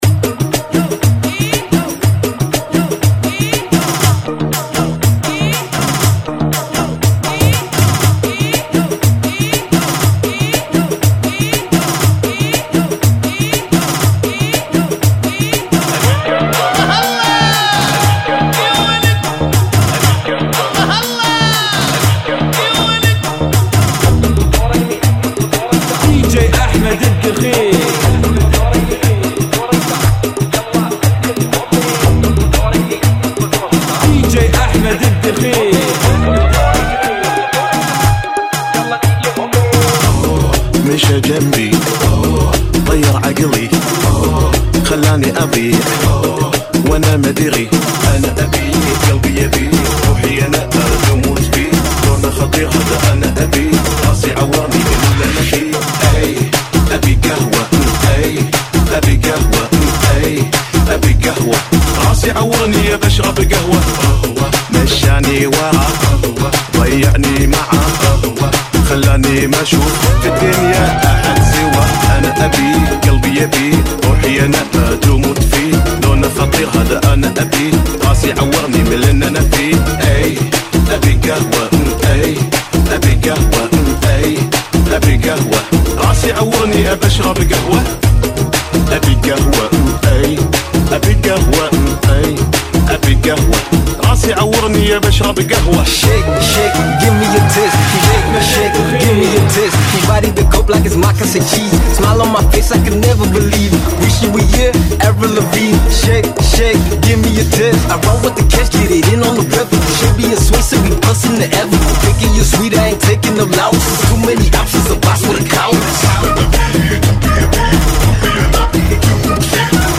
فصله كواسير - ريمكس